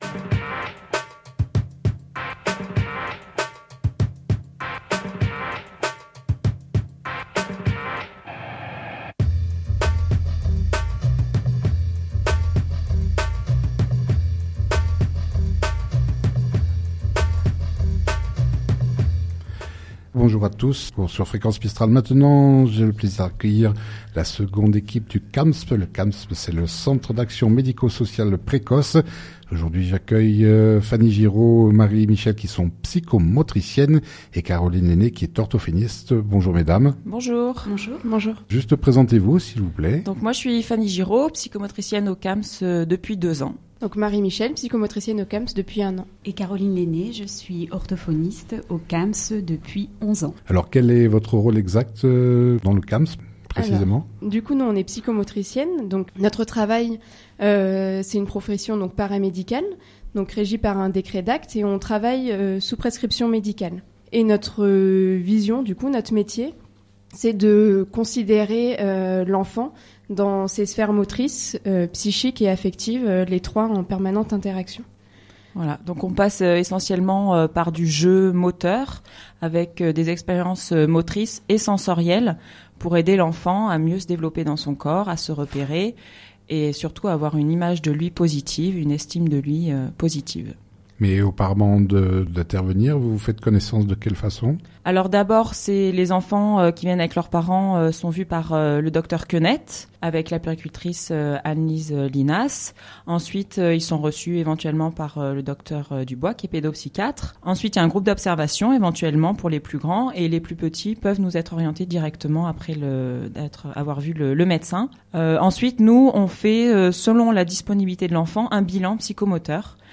En direct sur notre antenne de fréquence mistral